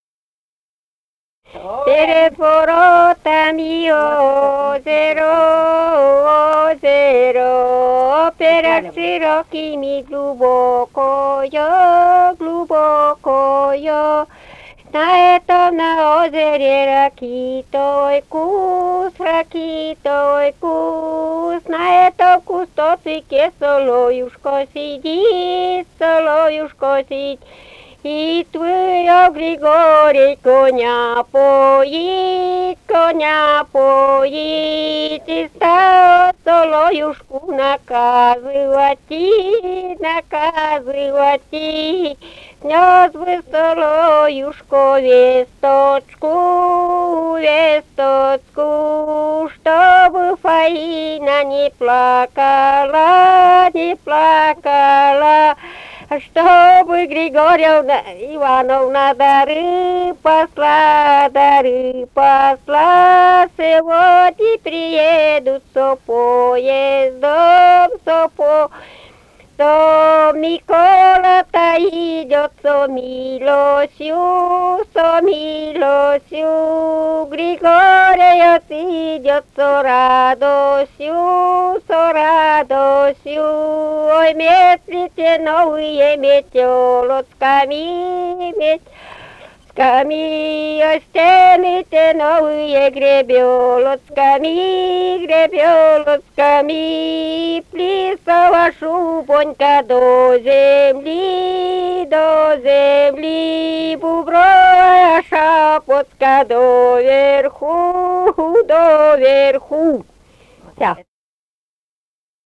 «Перед воротами озеро» (свадебная).